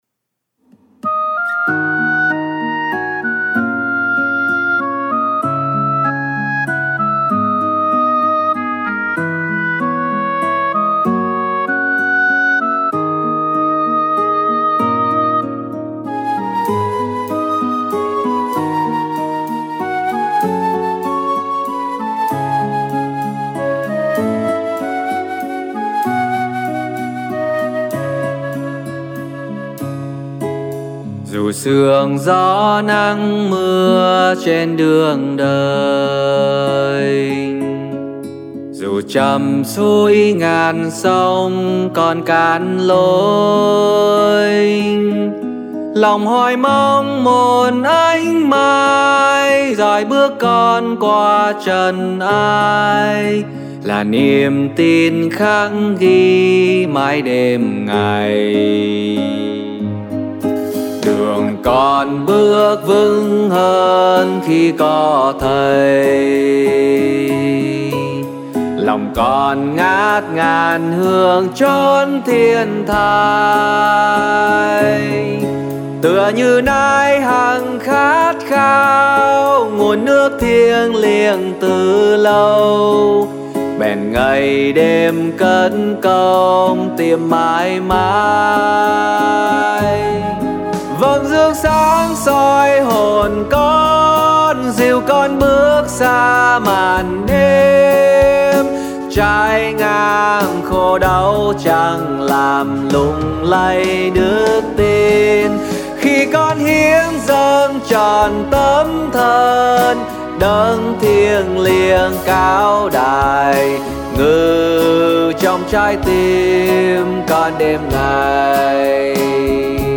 Nam (C#m) / Nữ (F#m)